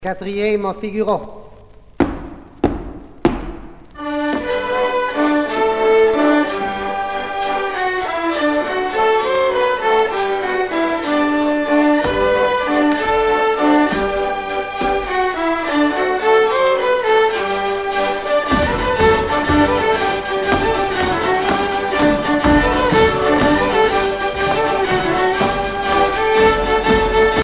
danse : quadrille
Pièce musicale éditée